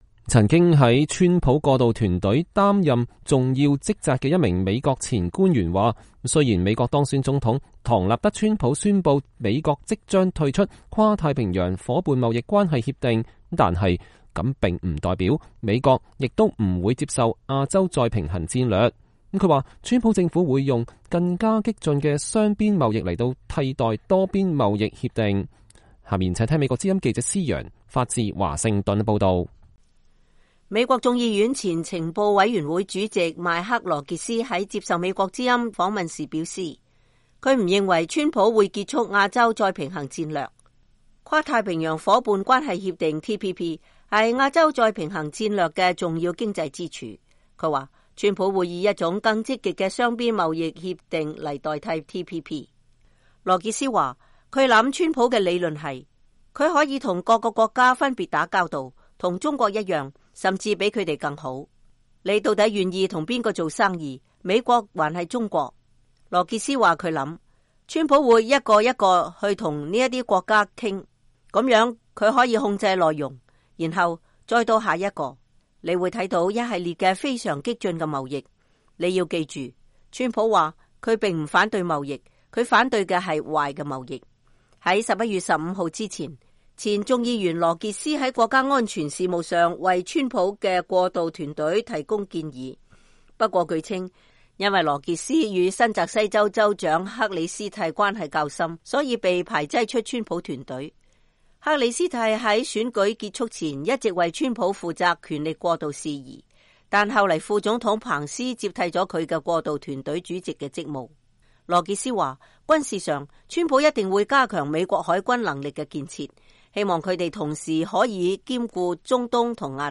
美國眾議院前情報委員會主席邁克·羅傑斯（Mike Rogers）在接受美國之音訪問時說，他不認為川普會結束亞洲再平衡戰略。